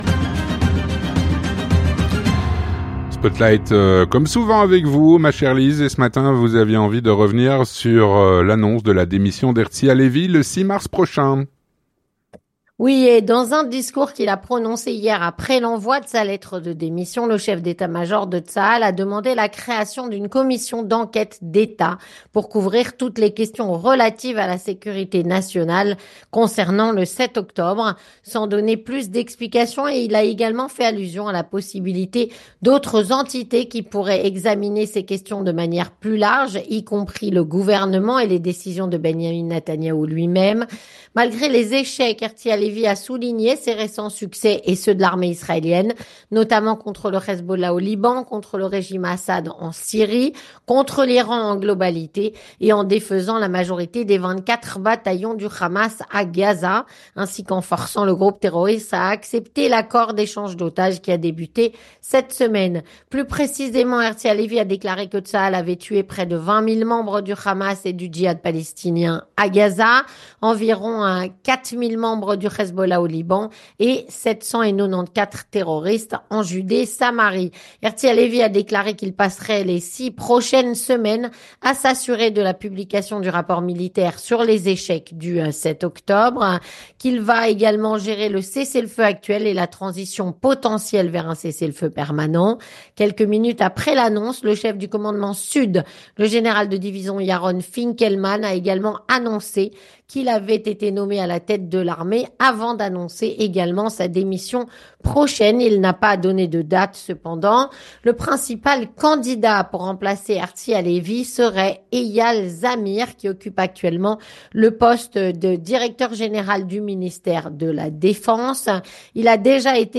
3. Les Chroniques de la Matinale